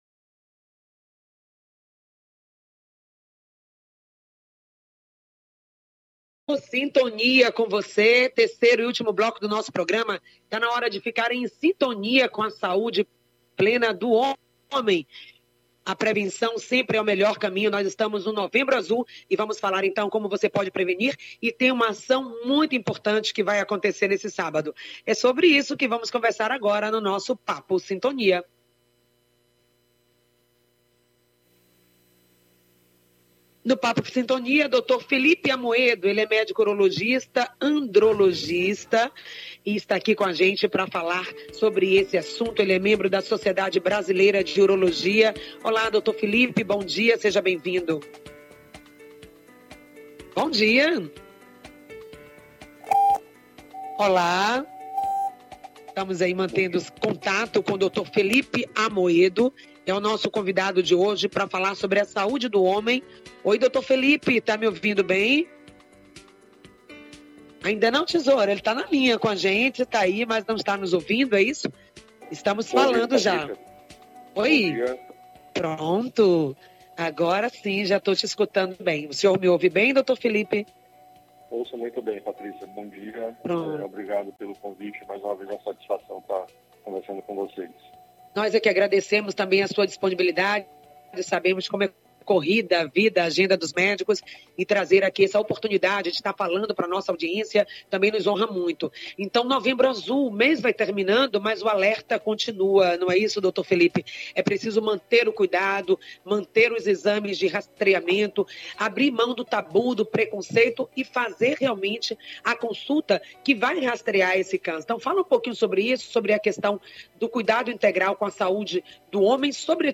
O programa Em Sintonia acontece de Segunda à sexta das 9 às 10h, pela Rádio Excelsior AM 840.